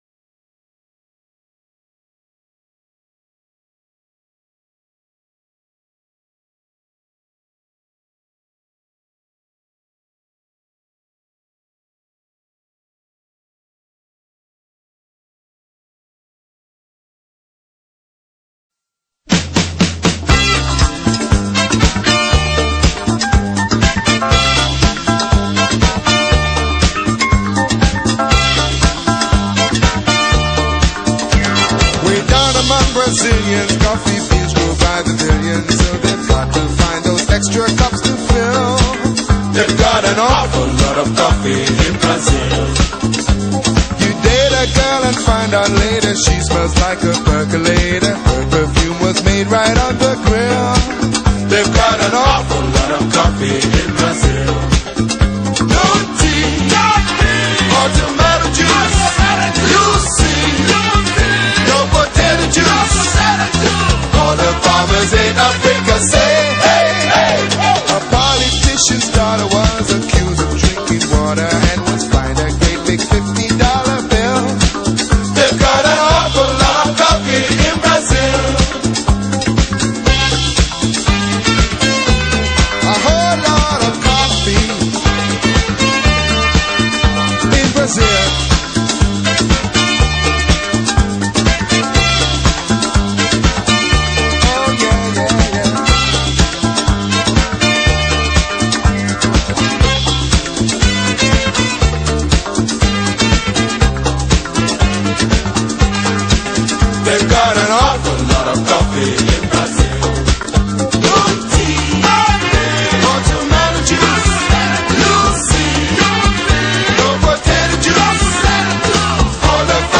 coffee songs